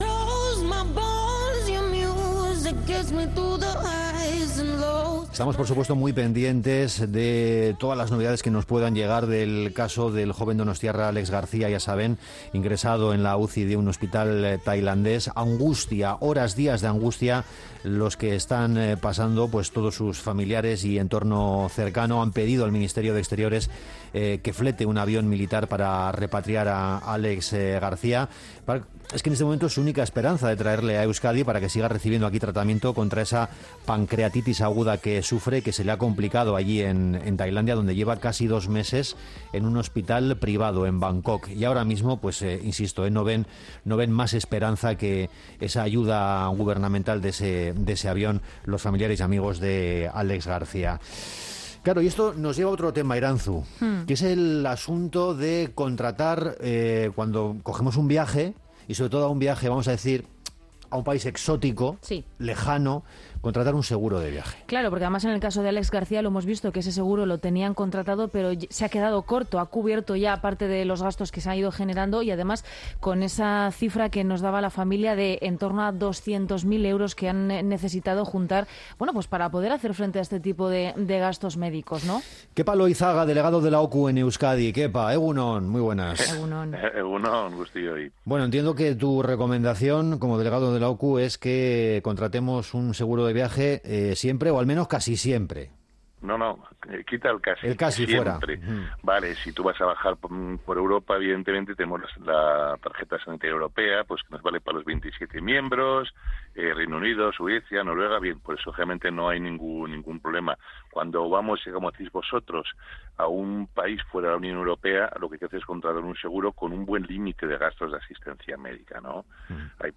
Entrevistado